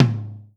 Tom 03.wav